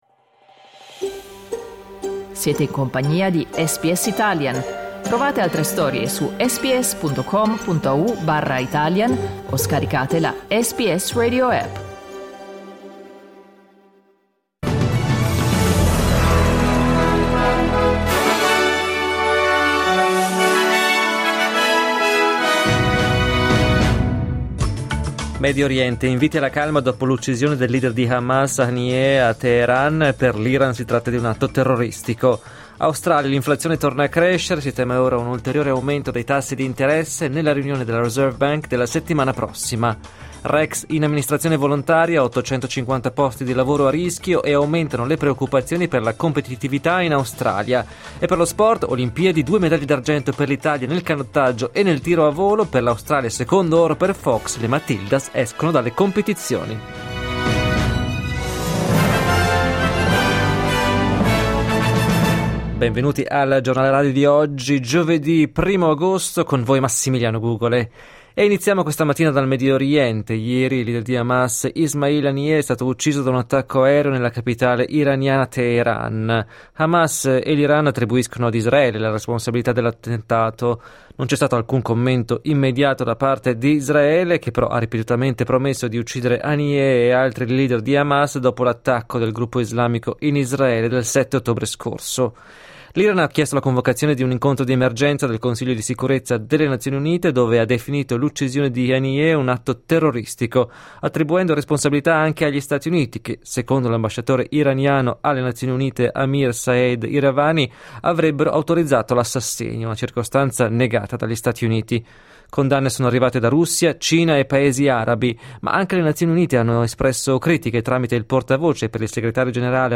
Giornale radio giovedì 1 agosto 2024
Il notiziario di SBS in italiano.